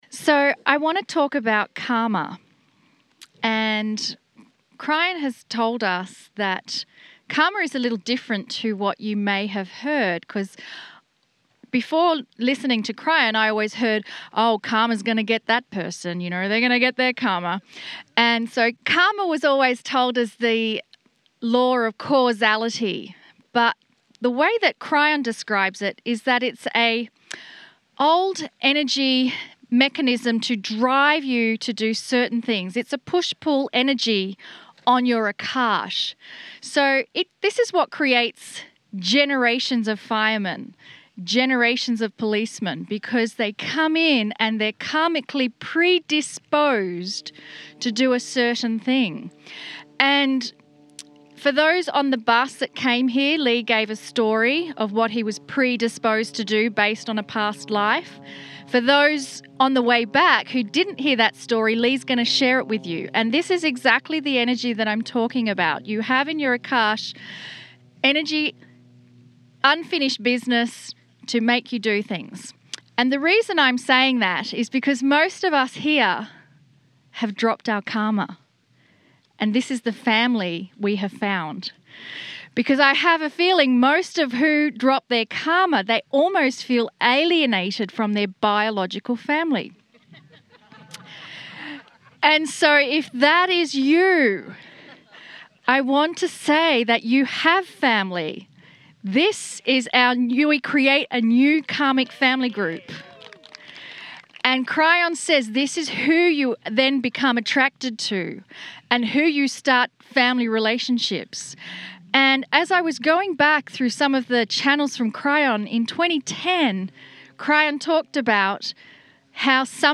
MONUMENT VALLEY TOUR February 9-16